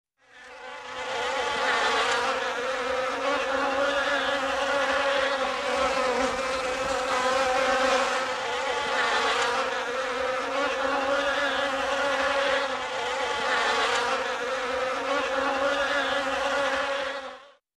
Звуки насекомых
рой гудящих мух